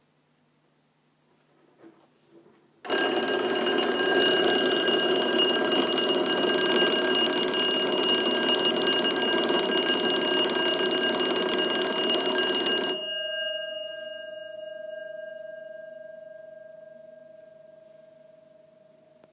Bronzové stolní hodiny / budík 23x8x30cm
budik_zvoneni_dek7878.m4a